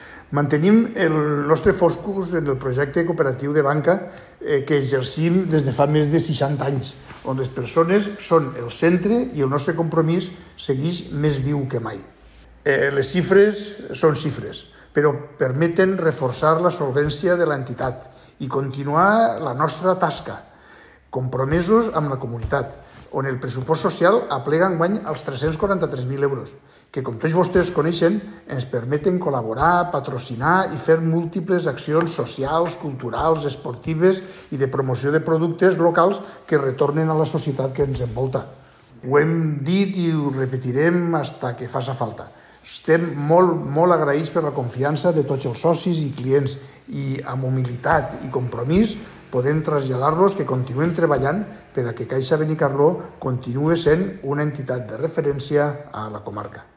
Caixa Benicarló donava a conèixer el seu informe anual este dimecres 4 de juny en Assemblea General Ordinària, celebrada a l’Auditori Pedro Mercader de Benicarló, davant les seues sòcies i socis.